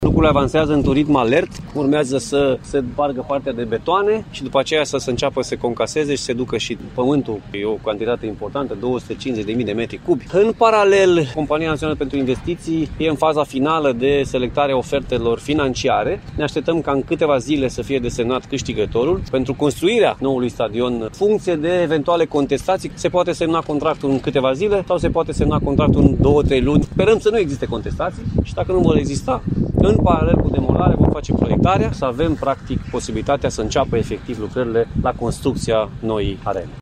Președintele Consiliului Județean Timiș, Alfred Simonis a anunțat că zilele viitoare se va afla și numele câștigătorului pentru construcția noii arene.